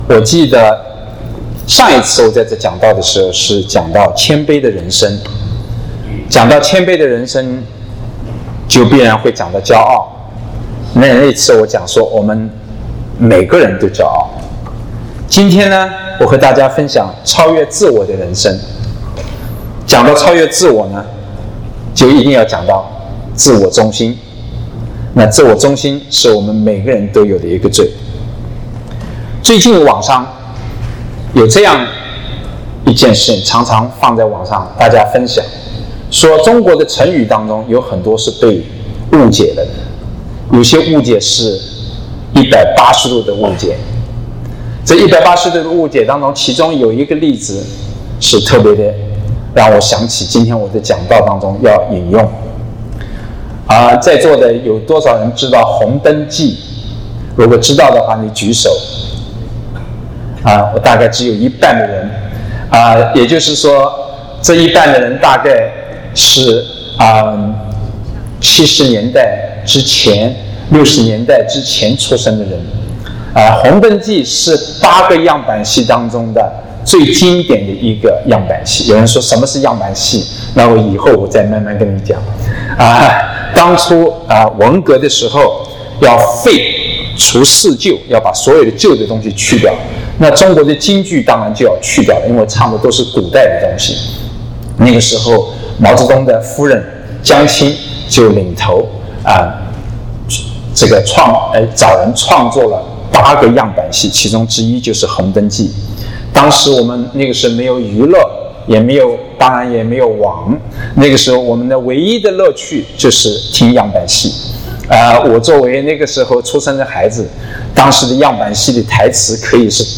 證道
超越憂慮的喜樂 » Category English (132) Events (83) Sunday Worship (682) Testimony (10) 无论做什么,都要从心里做,像是给主做的,不是给人做的。